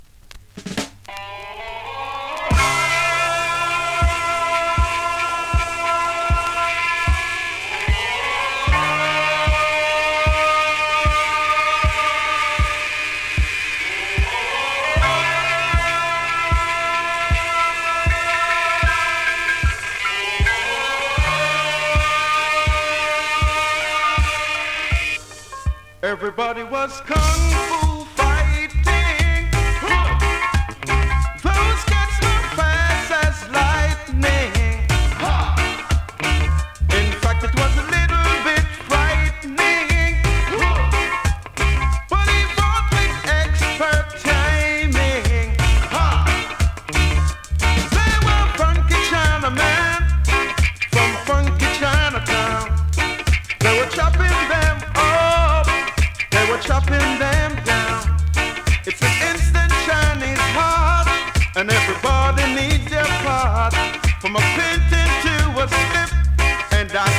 フチに一か所クモリ有、わずかにノイズ有
スリキズ、ノイズかなり少なめの